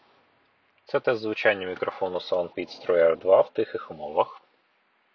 Мікрофон:
Співбесідник точно почує вас, чітко розбере те що ви кажете, навіть на гучній вулиці, а в тихій кімнаті – тим паче.
В тихих умовах: